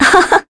Scarlet-vox-Happy2_kr.wav